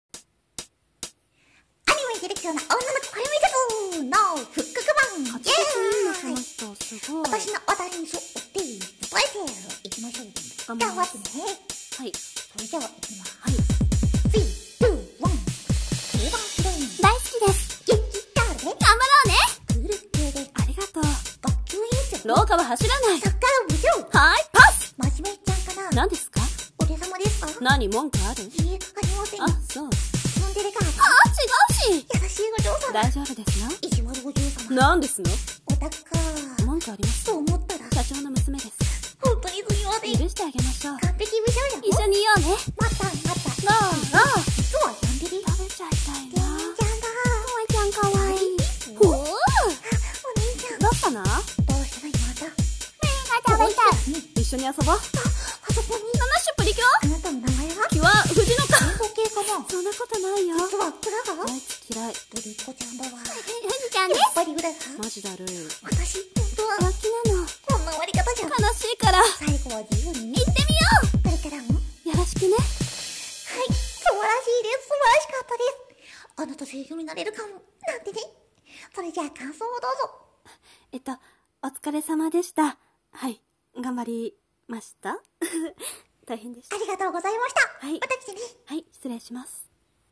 【声面接】アニメに出てきそうな女の子キャラ面接【復刻版】